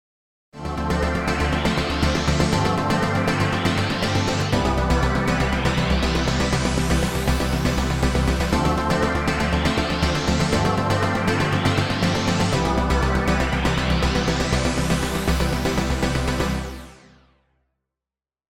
まずは、シンセのコードバッキングの音にしてみましょう。では、アルペジオのトラックだけをソロで聴いてみてください。